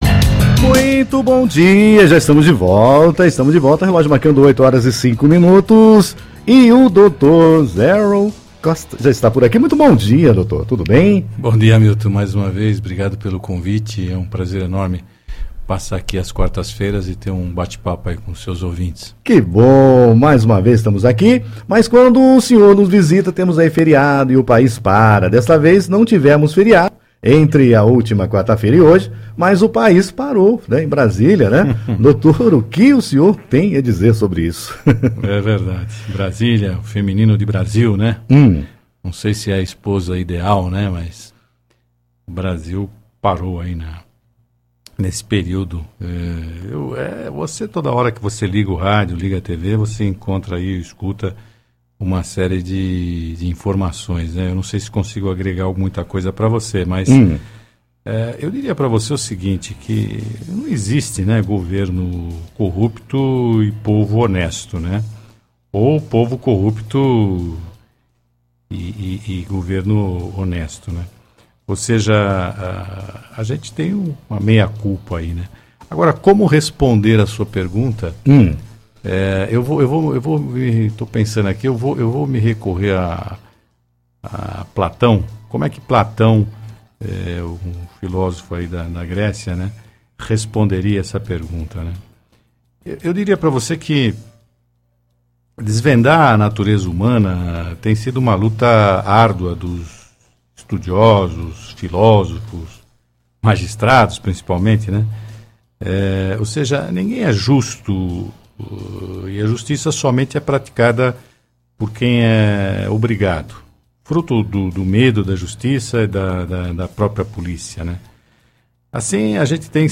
na rádio Nova Sumaré em 24/05/2017 – Liderança